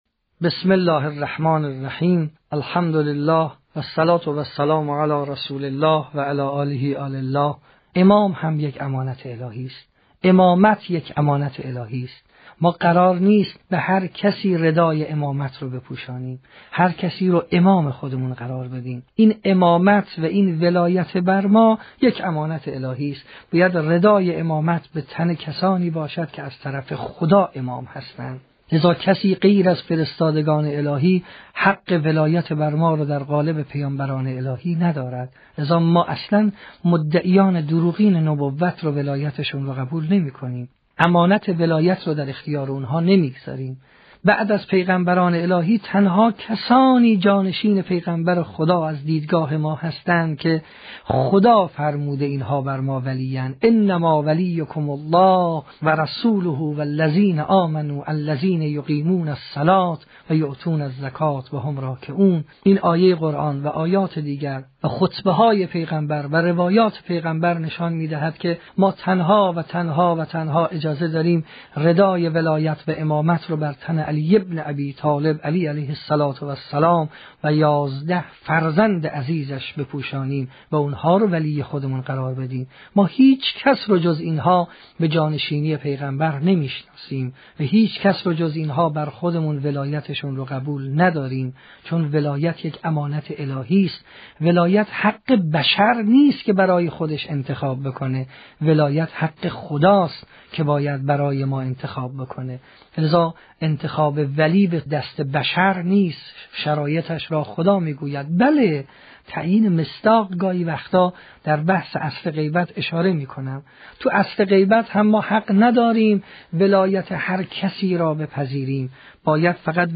سخنان